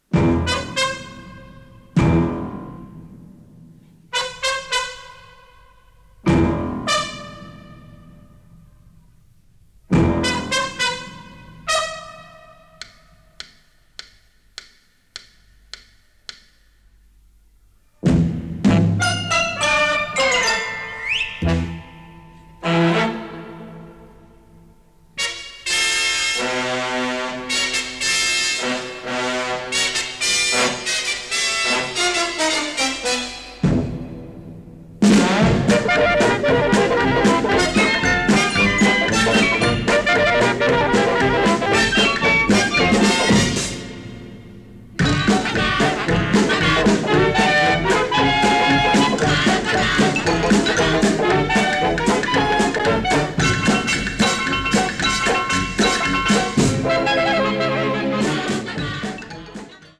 including the tango and Charleston
harpsichord and synth